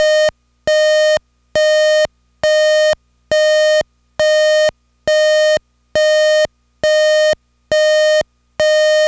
NAST 音量調整付バックブザー｜製品情報｜株式会社丸安商会
◆耐雨構造（本体のみ） ◆最大音量 87dB(±6dB) ◆外部ボリューム付き（コード長約1.5m） ※NBN系を除く ◆ボリュームで無段階音量調節可能 ※NBN系を除く ◆DC12V仕様 ◆DC24～48V仕様
NAST NBシリーズ ブザー音アラーム ◆耐雨構造（本体のみ） ◆最大音量 87dB(±6dB) ◆外部ボリューム付き（コード長約1.5m） ※NBN系を除く ◆ボリュームで無段階音量調節可能 ※NBN系を除く ◆DC12V仕様 ◆DC24～48V仕様 サンプル音試聴 ※再生ボタンをクリックすると音が出ますのでご注意ください。